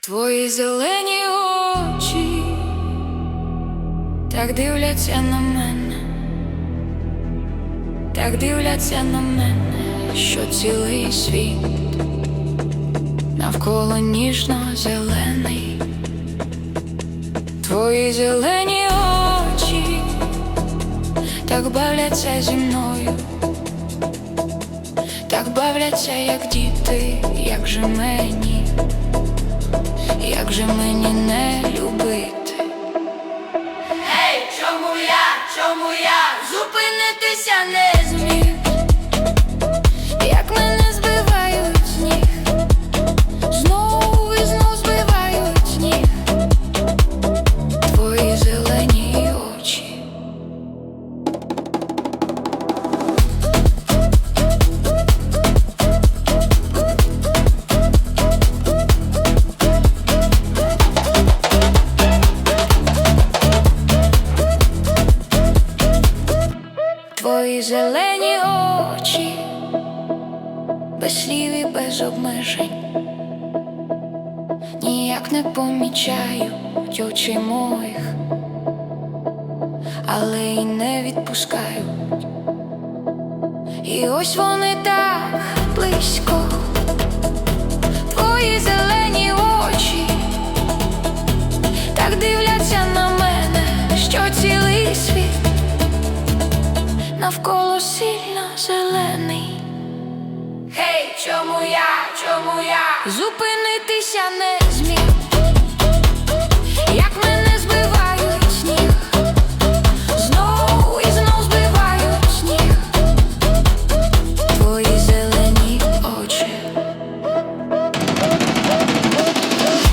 Afro House cover